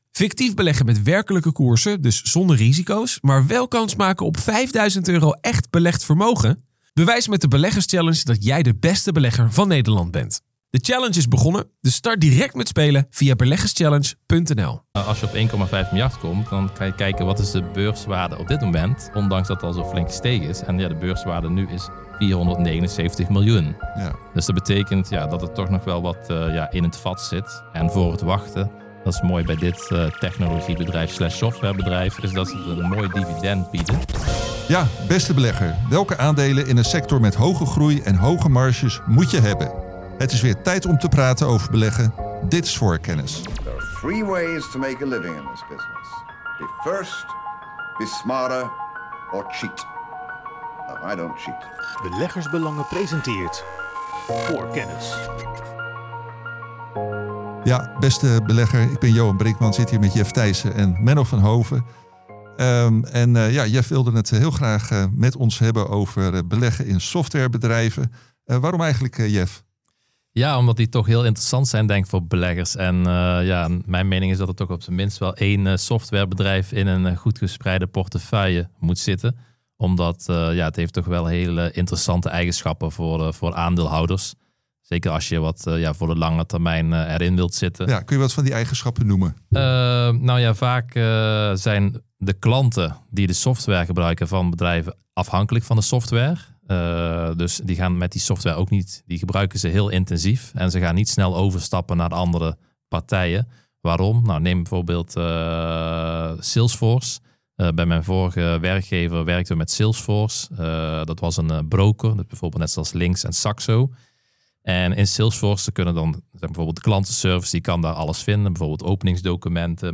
Onder leiding van verschillende gespreksleiders werpen de beleggingsexperts van Beleggers Belangen wekelijks een blik op de financiële markten.